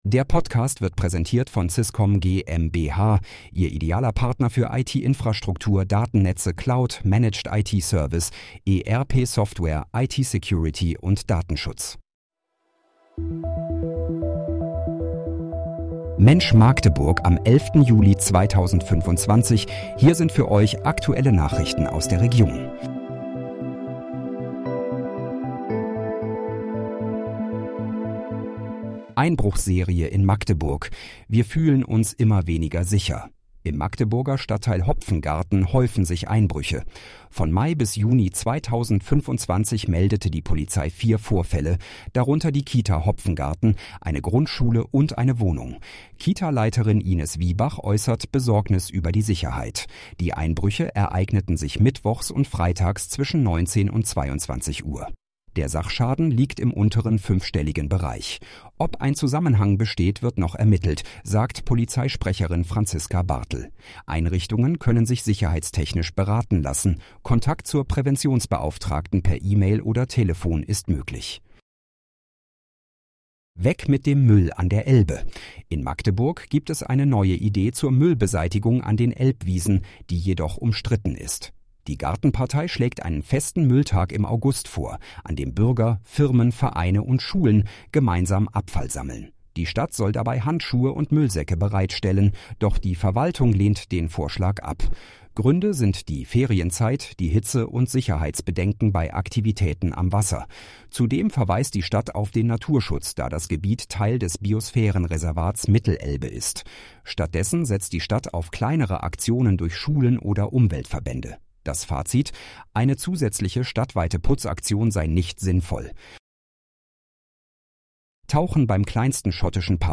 Mensch, Magdeburg: Aktuelle Nachrichten vom 11.07.2025, erstellt mit KI-Unterstützung